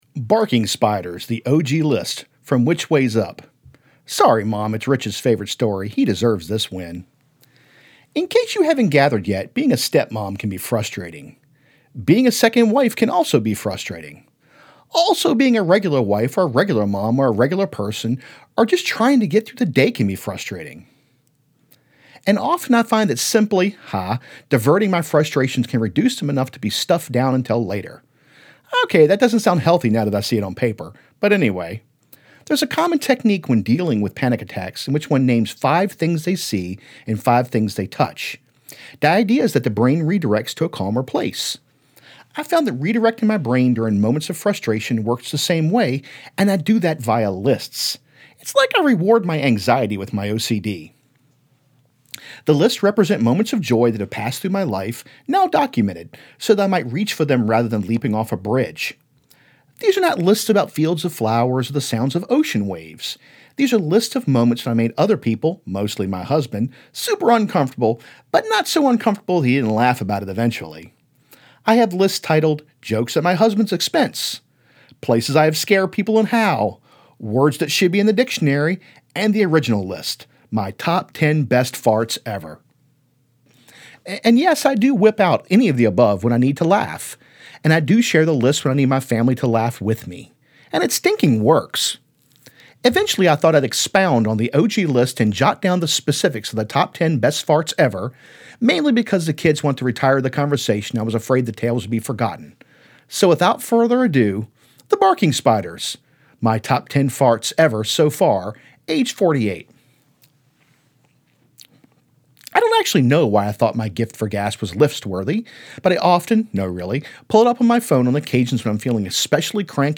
The Audiobook